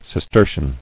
[Cis·ter·cian]